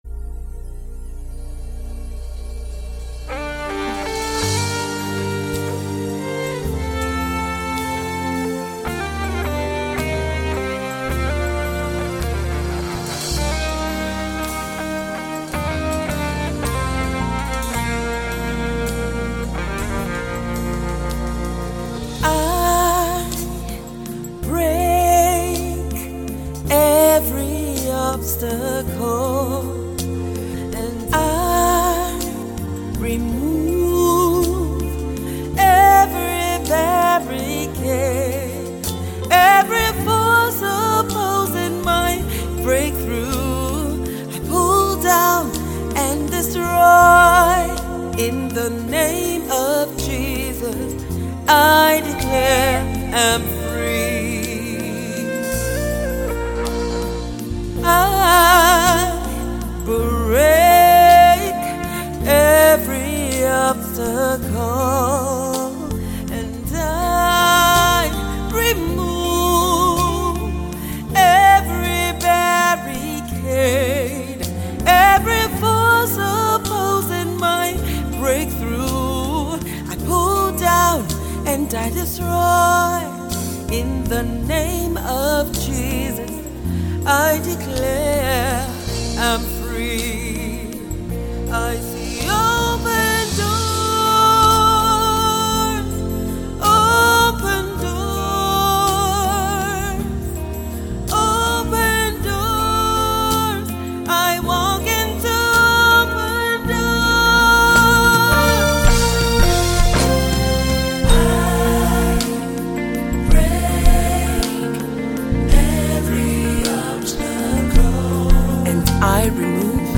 gospel
uplifting single